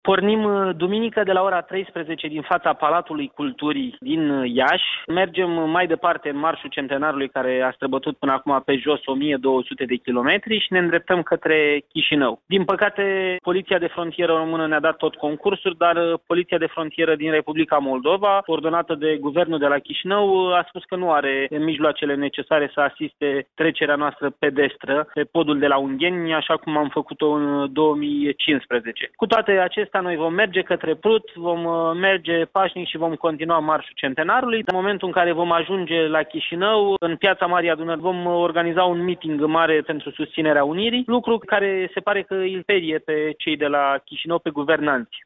George Simion, preşedintele Acţiunii 2012, susţine că poliția de Frontieră a Republicii Moldova nu ar dori să permită participanţilor la  Marșul Centenar să treacă frontiera de la Prut, pe la Ungheni, pe jos.